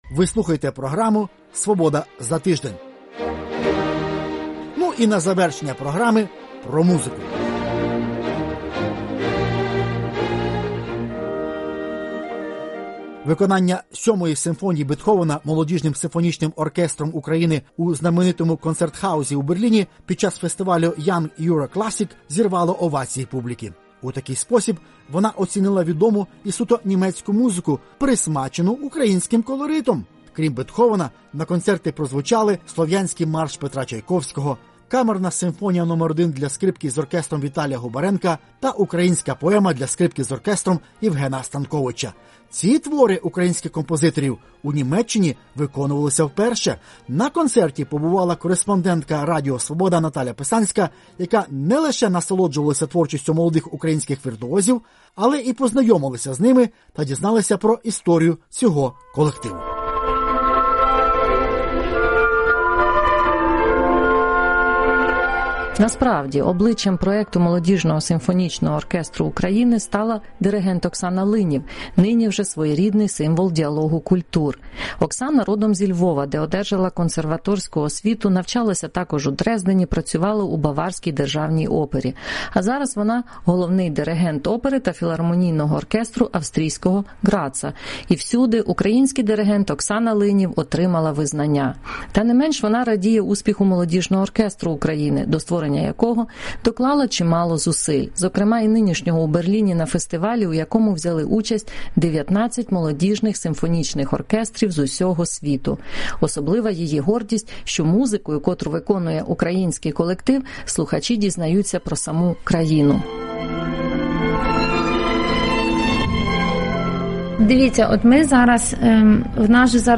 Під час концентру Українського молодіжного симфонічного оркестру в Берліні.
Берлін – Виконання Симфонії №7 Бетховена Молодіжним симфонічним оркестром України у знаменитому «Концертхаус» у Берліні під час фестивалю YoungEuroClassic, зірвало овації публіки.
відео Радіо Свобода
«Диригент дуже добре об'єднує весь оркестр», – зауважує інший відвідувач українського концерту.